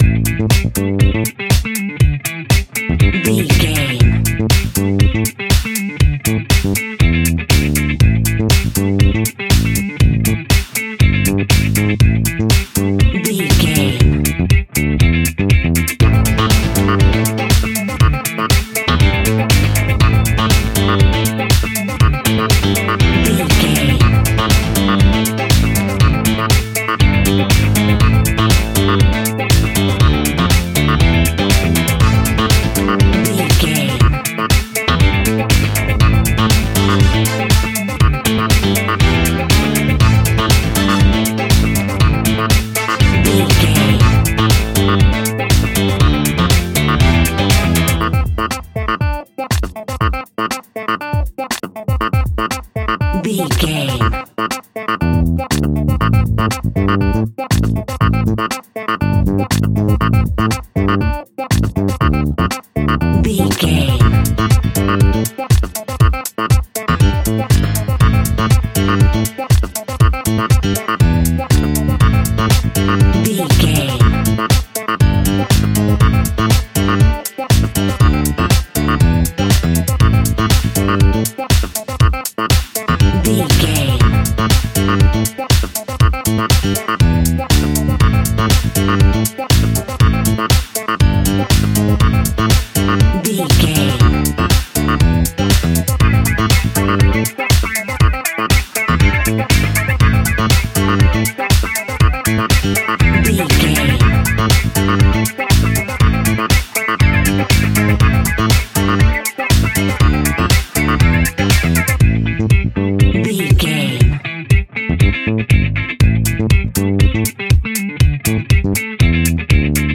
Aeolian/Minor
funky
groovy
uplifting
driving
energetic
bass guitar
electric guitar
drums
synthesiser
electric organ
brass
funky house
disco house
electronic funk
upbeat
synth leads
Synth Pads
synth bass
drum machines